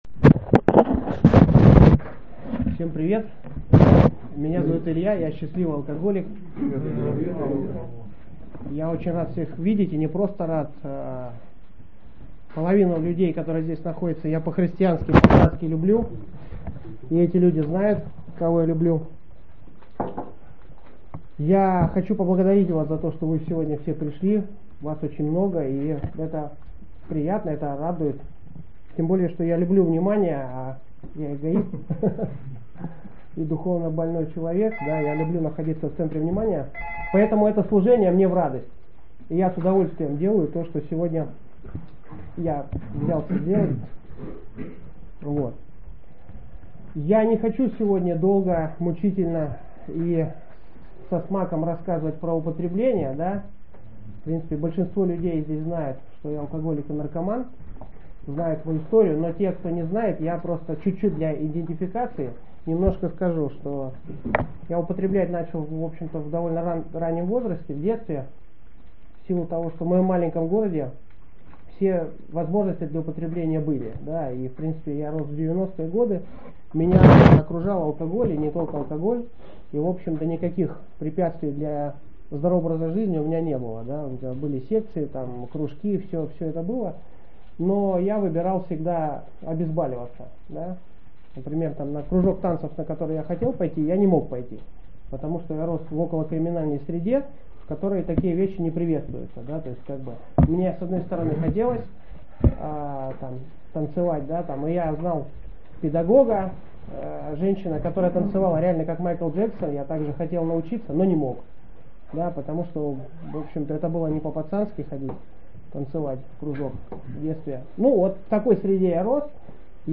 Запись спикерского от 29 марта 2025 - Отец Мартин